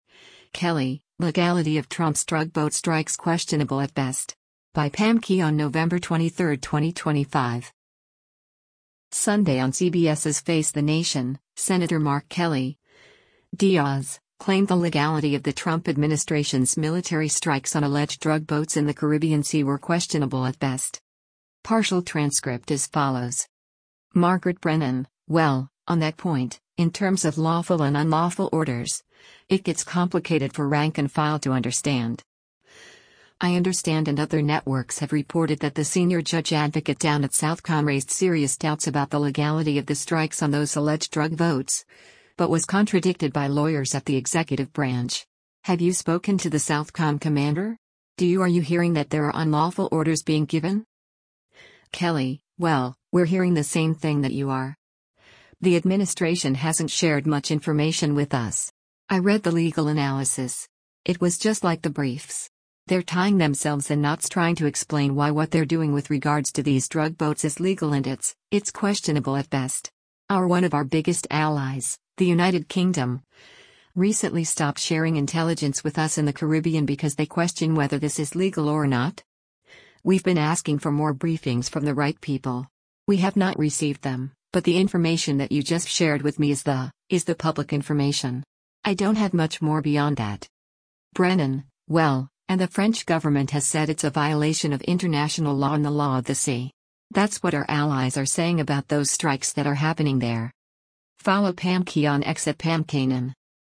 Sunday on CBS’s “Face the Nation,” Sen. Mark Kelly (D-AZ) claimed the legality of the Trump administration’s military strikes on alleged drug boats in the Caribbean Sea were “questionable at best.”